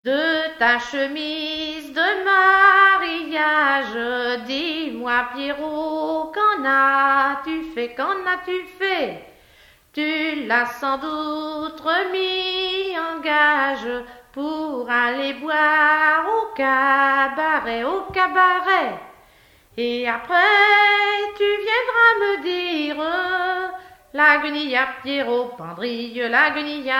Genre énumérative
chansons traditionnelles
Pièce musicale inédite